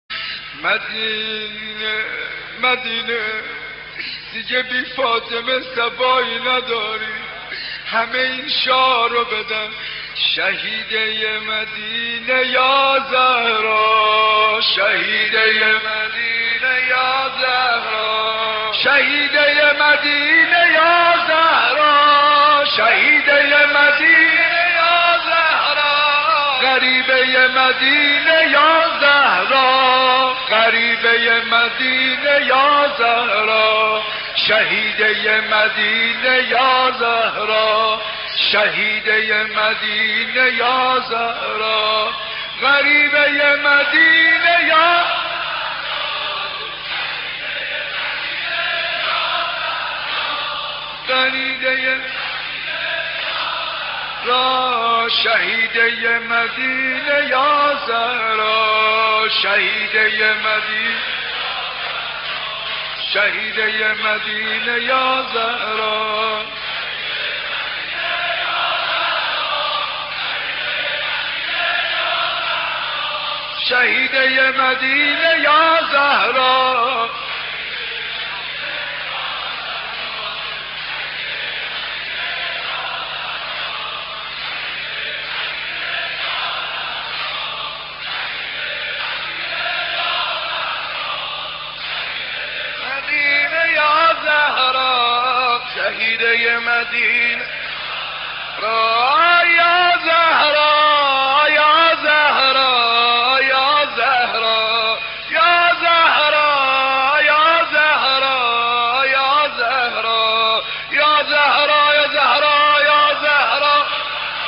مناجات
مداحی
روضه حضرت زهرا